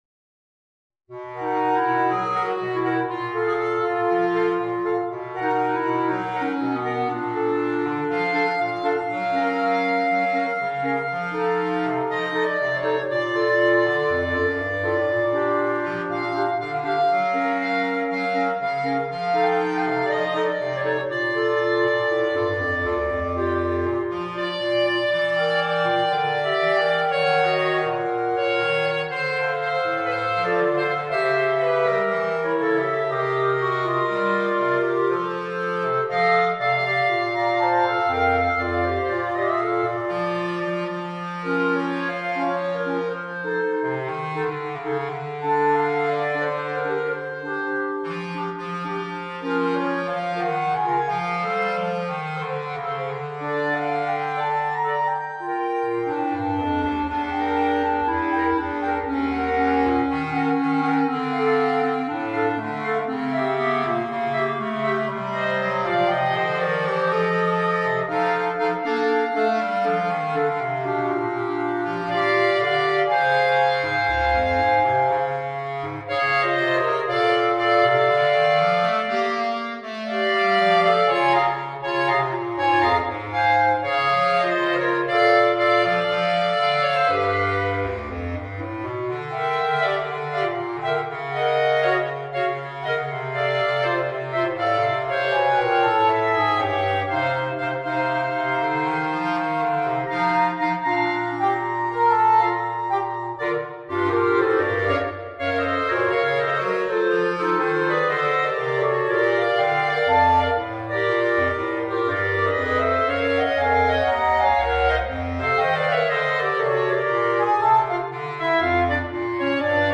for Clarinet Quartet
arranged for Clarinet Quartet